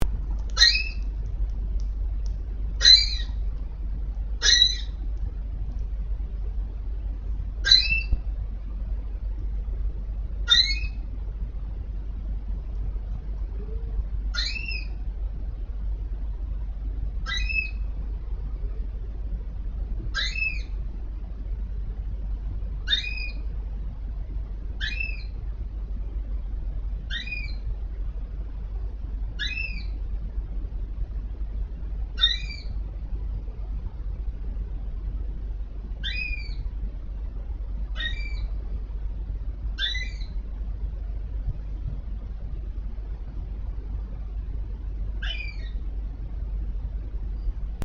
Loirs ou lérots ?
Une petite communauté de loirs s'est reformée et gambadait ce soir là dans les branches en poussant
de petits cris caractéristiques.